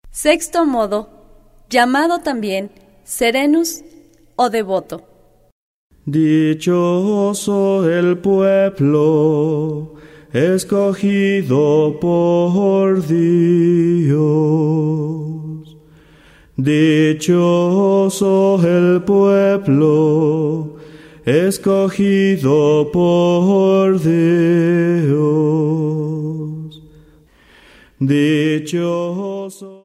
07 Sexto modo gregoriano.